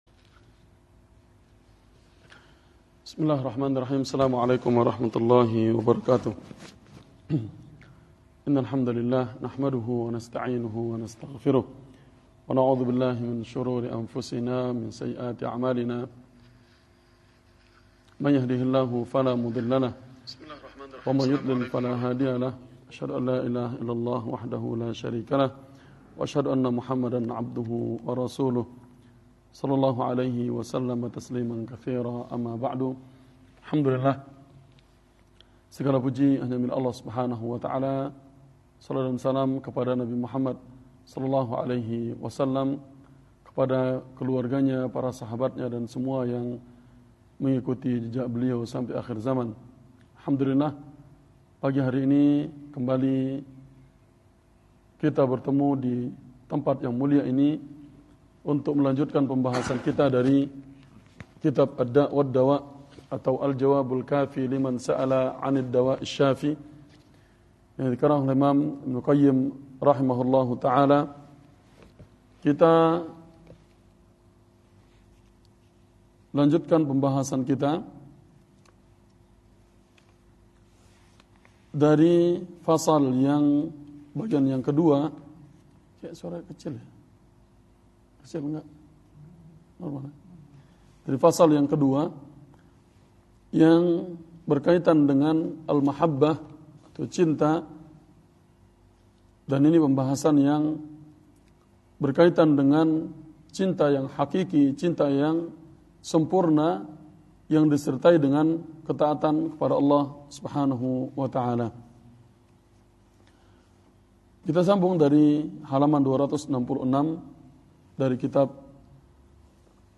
Kajian Senin